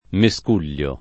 mescuglio [ me S k 2 l’l’o ] → miscuglio